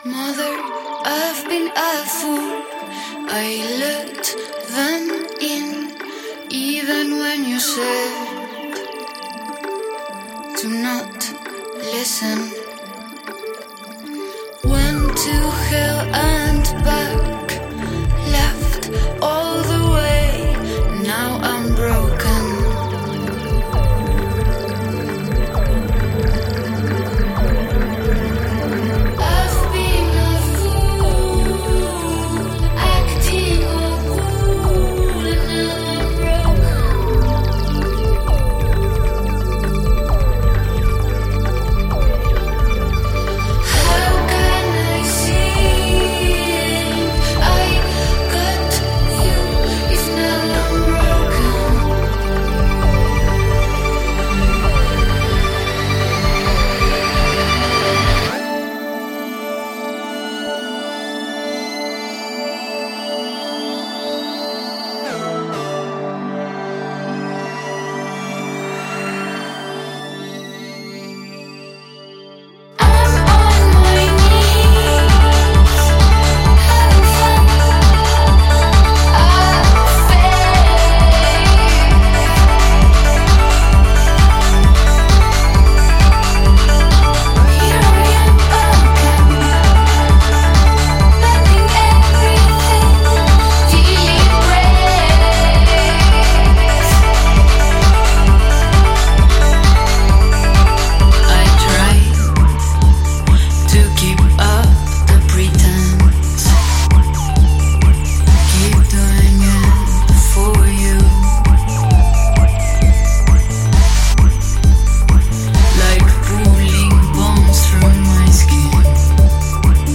с ее хрипловатым вокалом